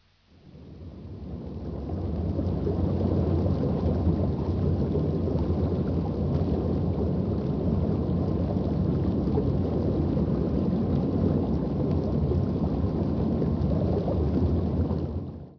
ore-floatation-cell.ogg